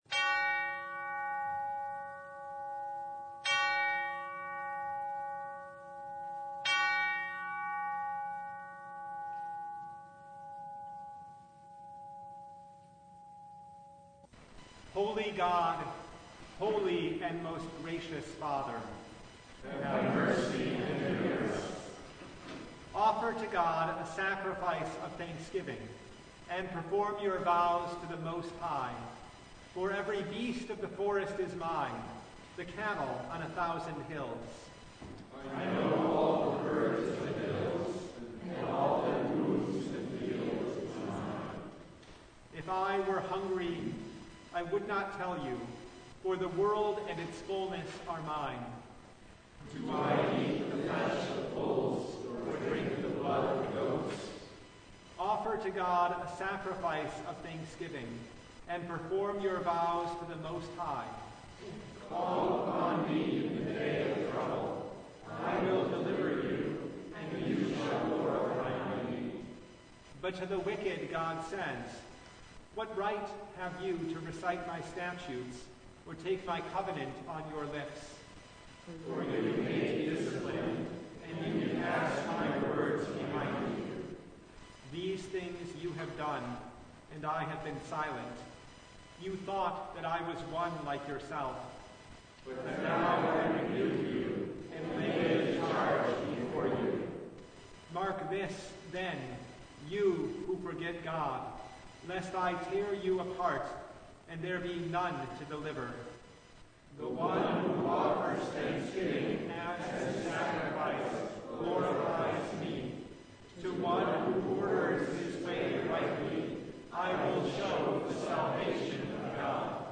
Service Type: Lent Midweek Noon